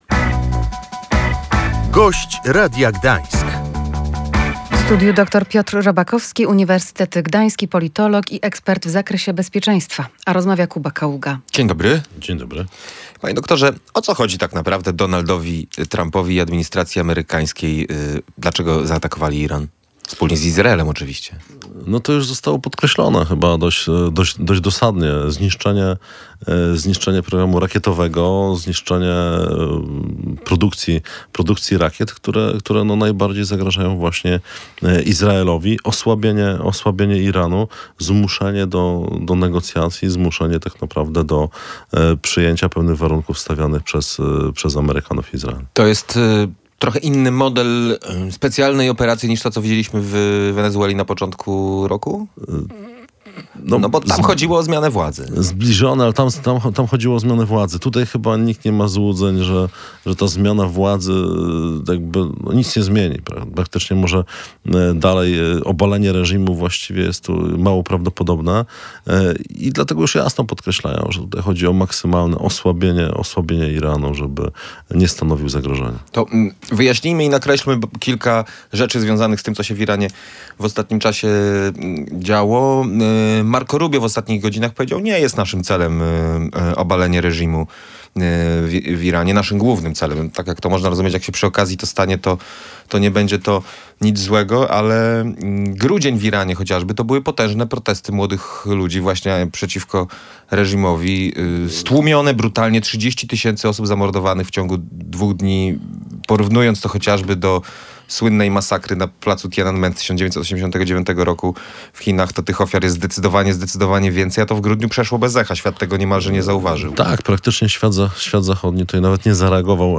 Gość Radia Gdańsk